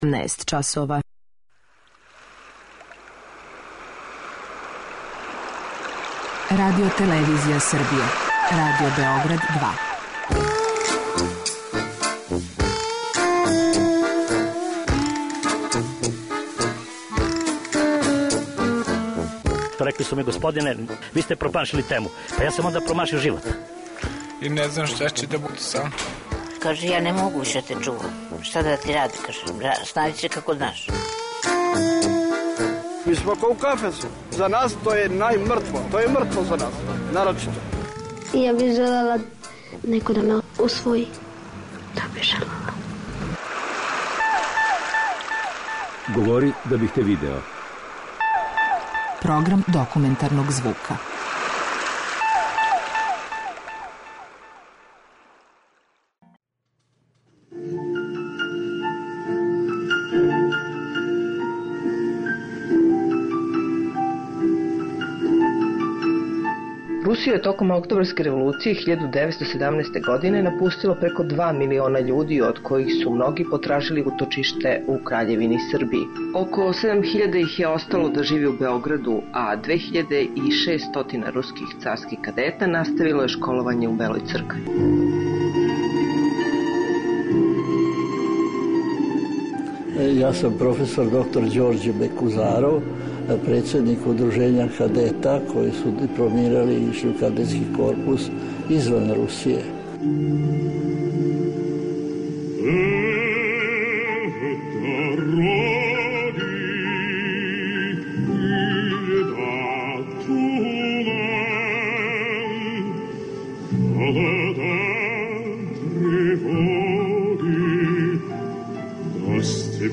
Соната за виолончело у а-молу, настала је 1897. године, као последње дело француског композитора Леона Боелмана. Посвећена је виолончелисти Жилу Делсару и почива на постромантичарском језику, са елементима импресионизма. Ову Сонату за виолончело Леона Боелмана, иновативног језика и композиционог приступа, слушаћемо у извођењу виолончелисте Андреа Наваре и пијанисткиње Ани Д'Арко.